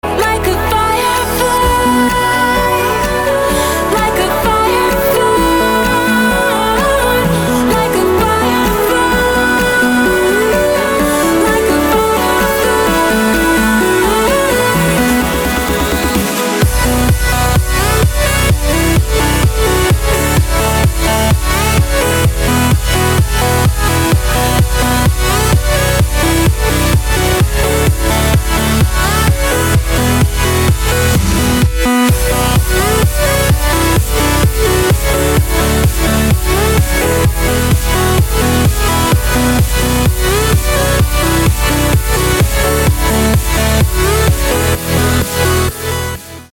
• Качество: 320, Stereo
женский вокал
dance
Electronic
club
красивый женский голос
Trance